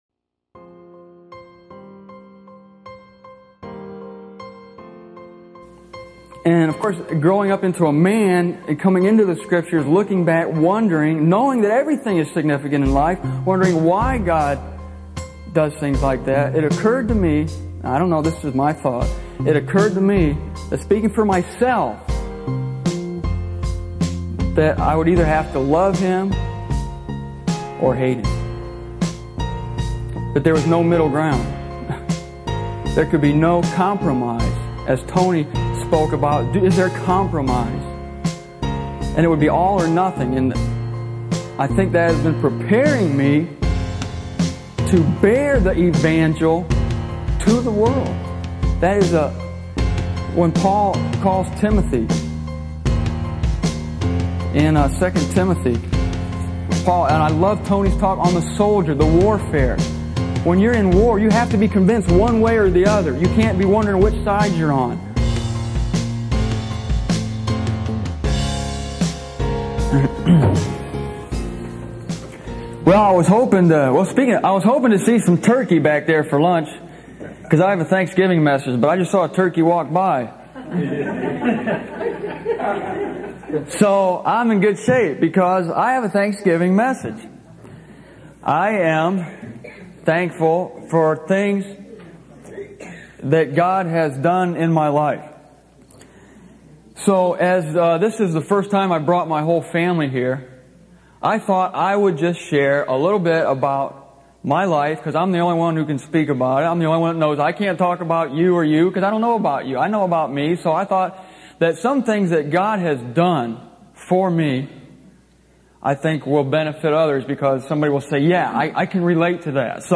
Listening to this tape 26 years later, I regret that I rushed through it.